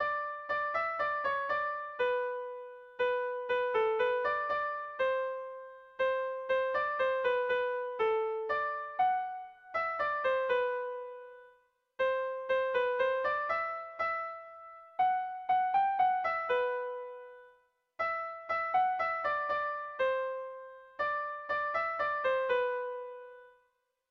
Erlijiozkoa
Zortziko txikia (hg) / Lau puntuko txikia (ip)
ABDE